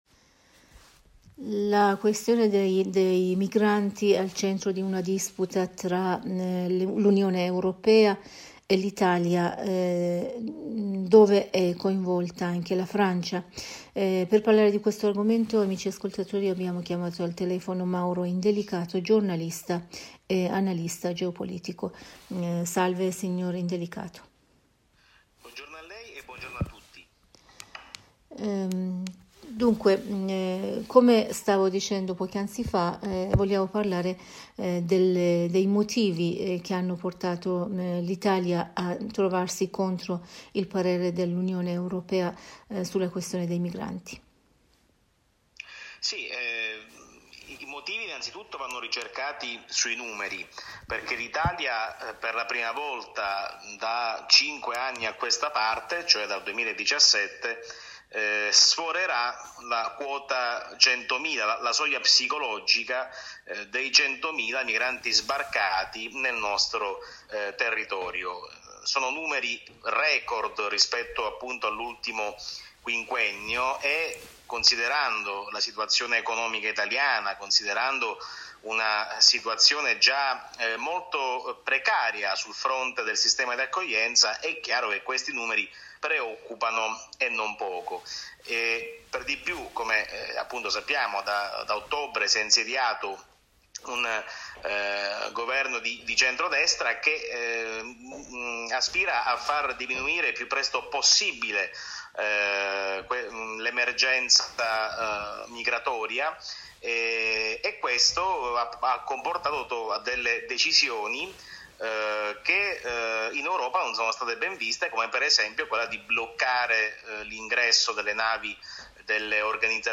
in un collegamento telefonico